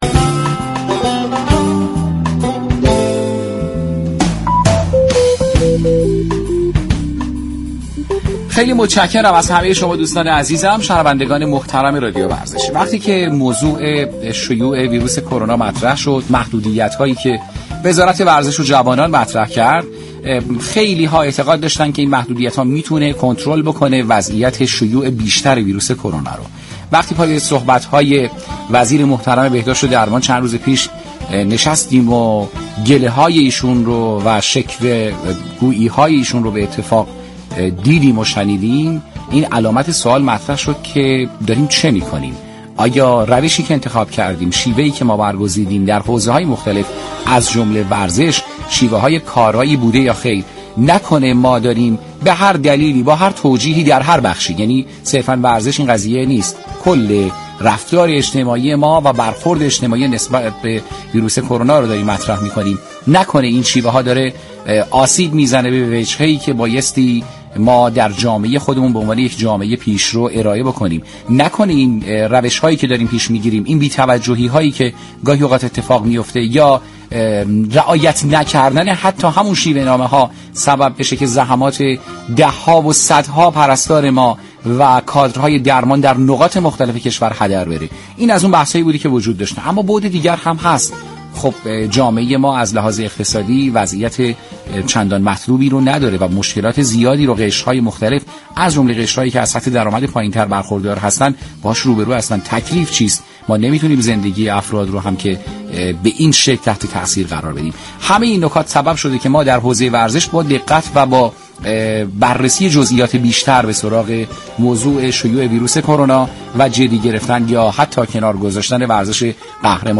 برنامه